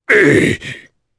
Kaulah-Vox_Damage_jp_02.wav